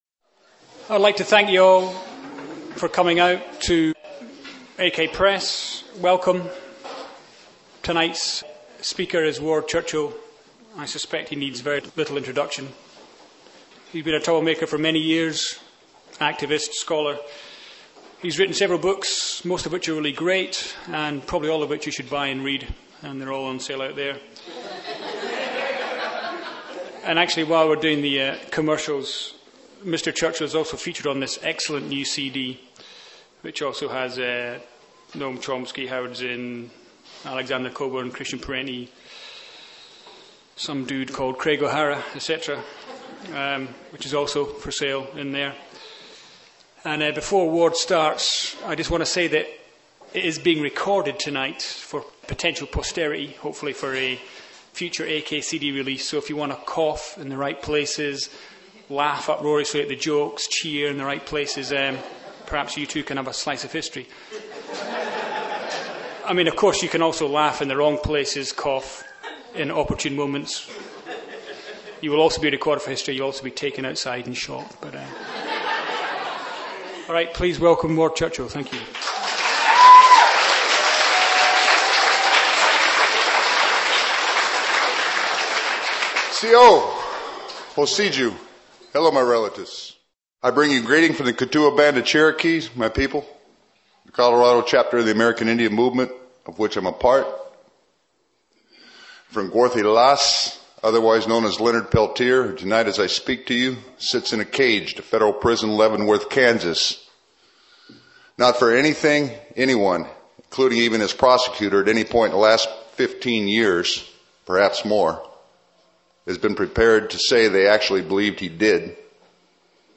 In the following lecture Ward Churchill challenges the left on their tactics and discredits, what he calls, pacifism as pathology. That is not to say he discredits pacifism, quite the opposite, Ward advises that the left shouldn’t be dogmatic in only supporting non-violent movements, and that they should not get non-violence confused with non-confrontation.